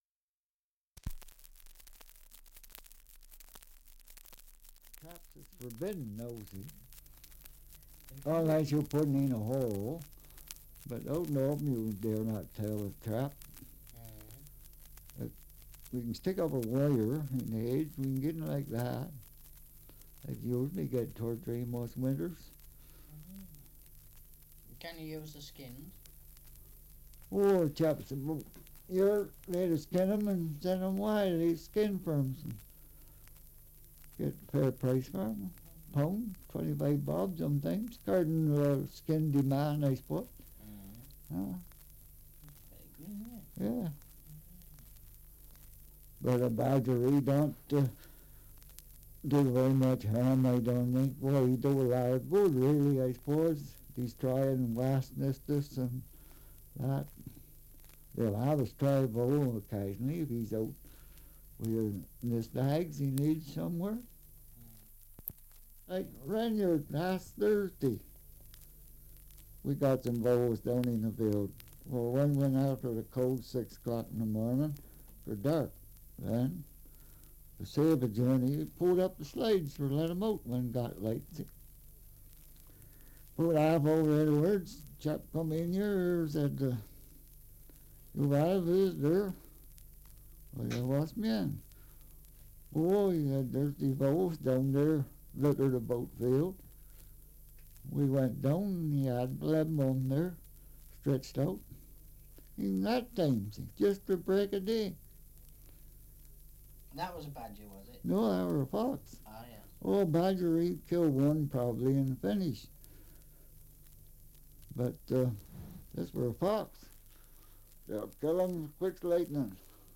2 - Survey of English Dialects recording in Ansty, Dorset
78 r.p.m., cellulose nitrate on aluminium